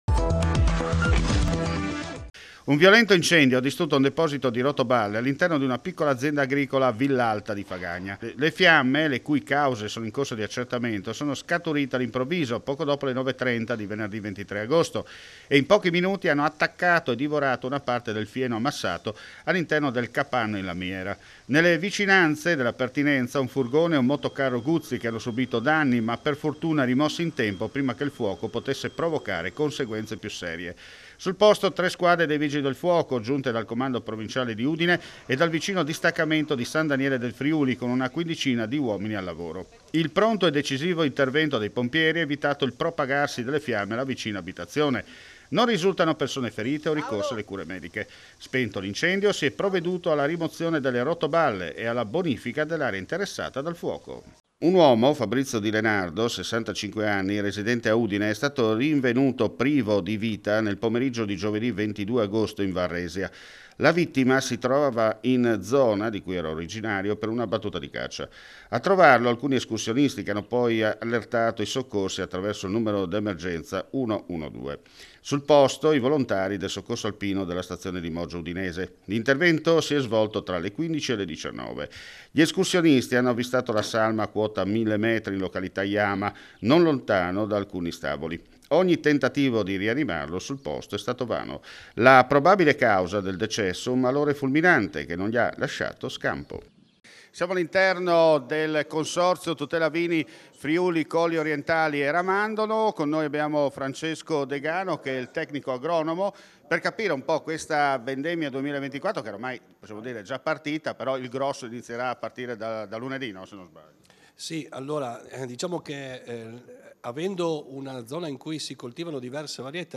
FRIULITV GIORNALE RADIO: LE ULTIME NOTIZIE DEL FRIULI VENEZIA GIULIA